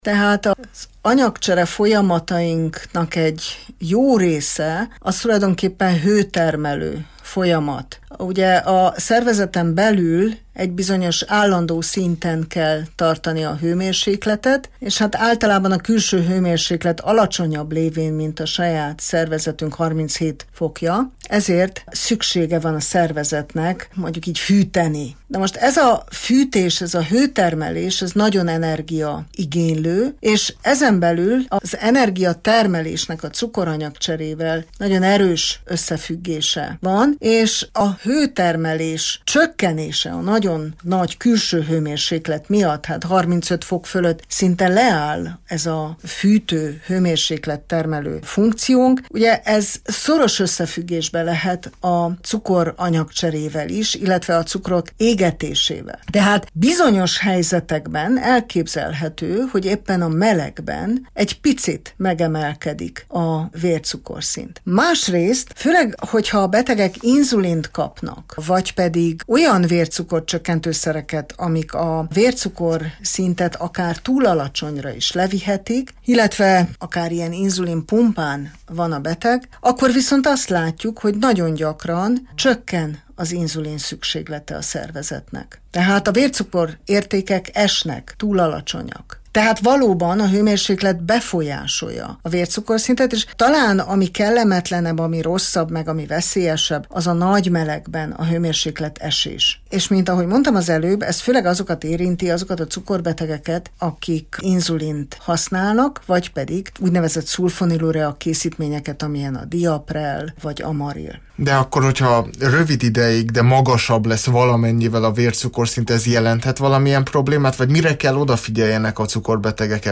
Ebből hallhatnak most egy részletet.